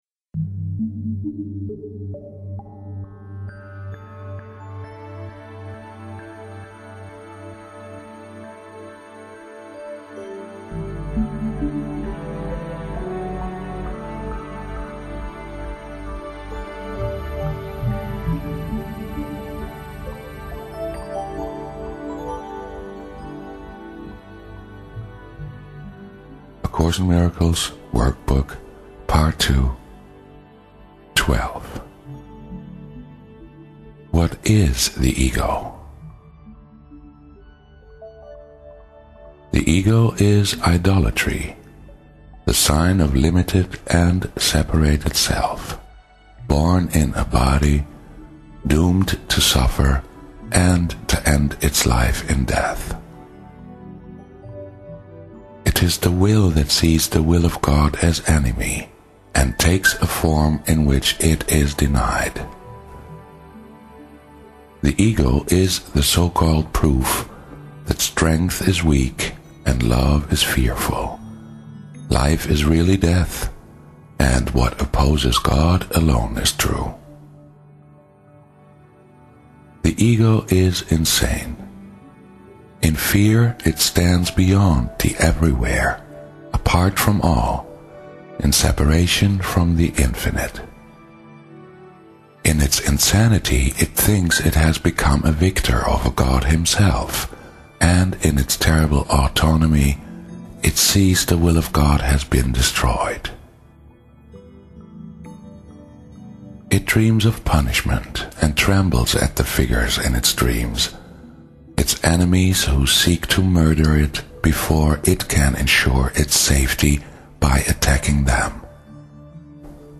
WB_ACIMRadio_WhatIsTheEgo.mp3